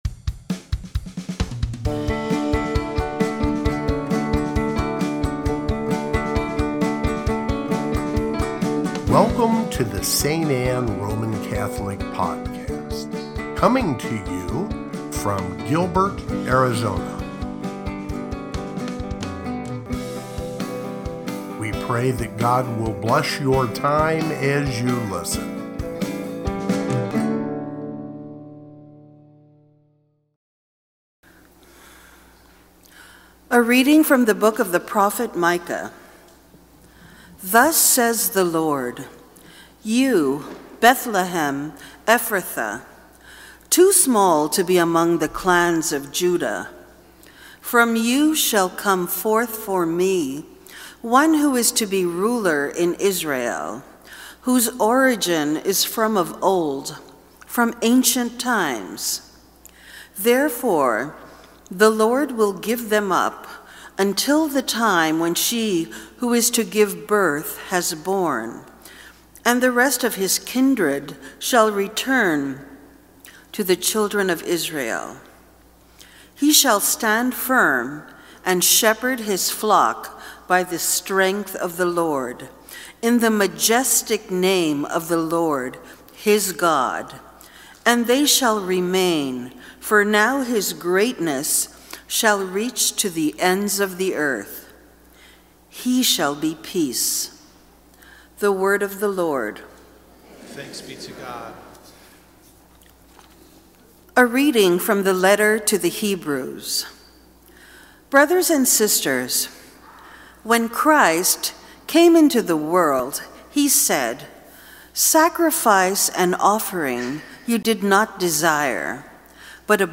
Fourth Sunday of Advent (Homily)